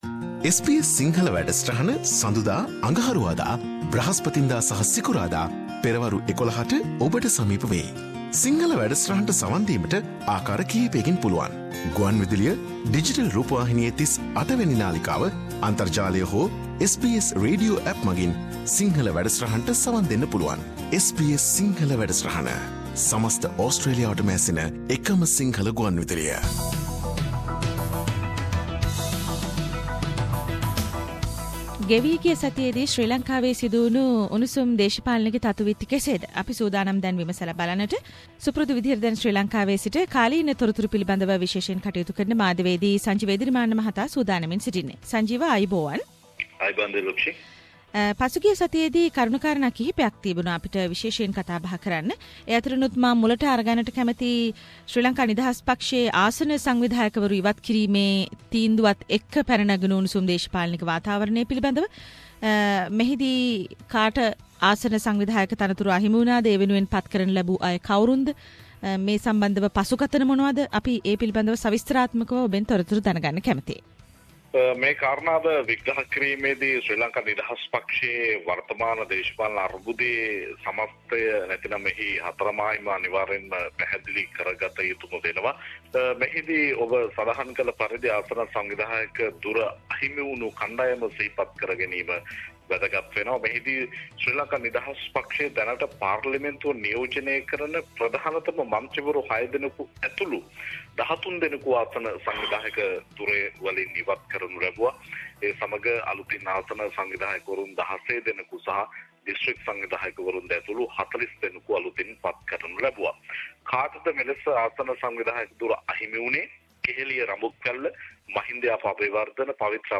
SBS Sinhalese weekly Sri Lankan news wrap – Arising flams for a new political party to kick off the joint government.